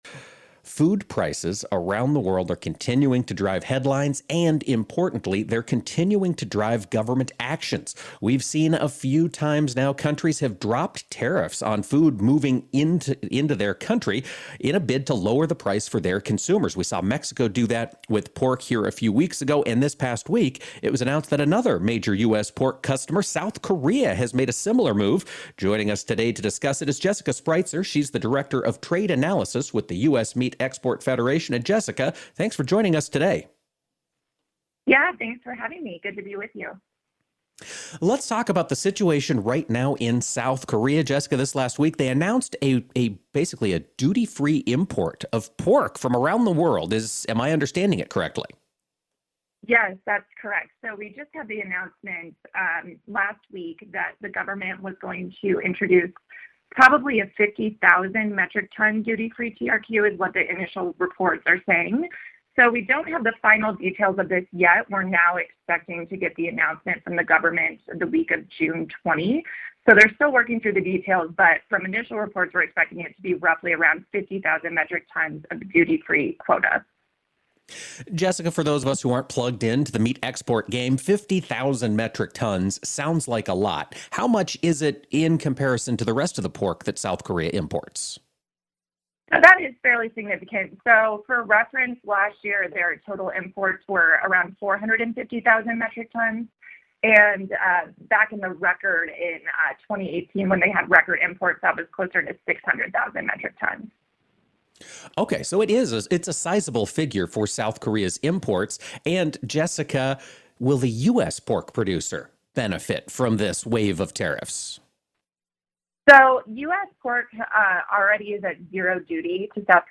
a discussion of South Korea's decision to open a duty-free quota on imported pork as part of an effort to combat food price inflation.